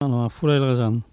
Localisation Sallertaine
Langue Maraîchin
Catégorie Locution